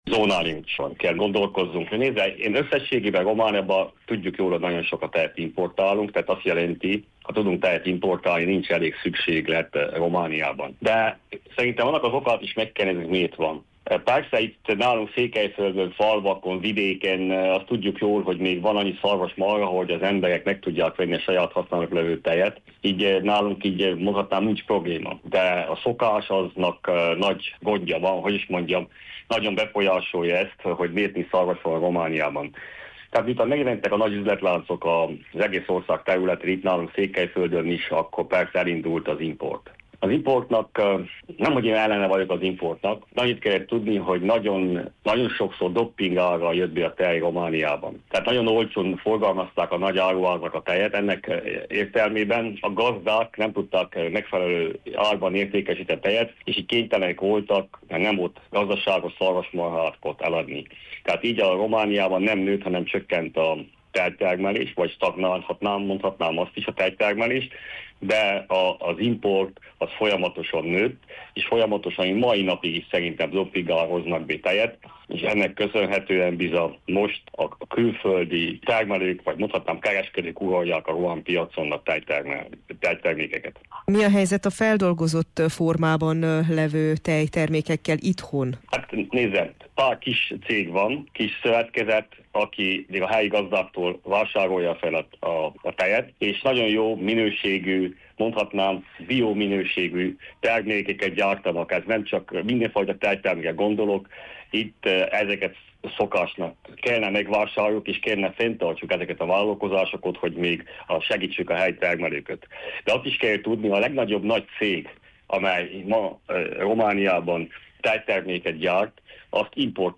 Fejér László Ödön képviselőt kérdezte